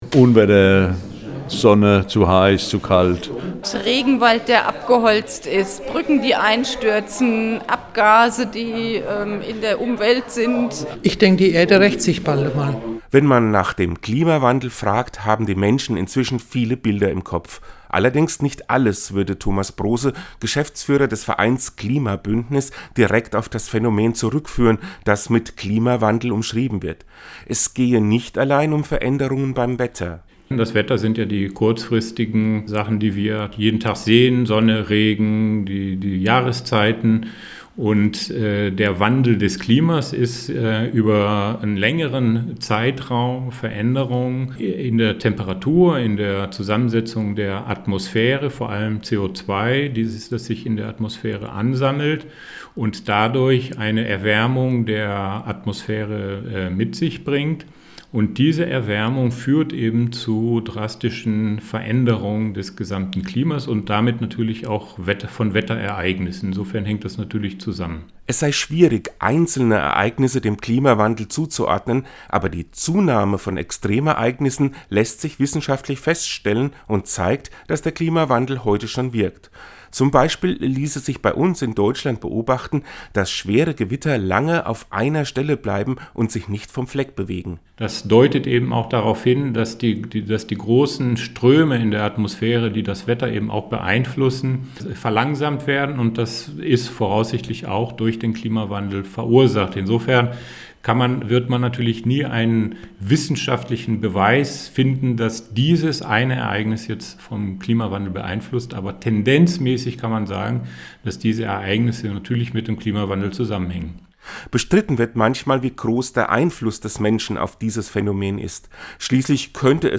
Und er hat zunächst herum gefragt, welche Bilder denn spontan im Kopf entstehen, wenn man das Wort "Klimawandel" hört. Seinen Radiobeitrag finden Sie unten als Download!